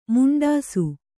♪ muṇḍāsu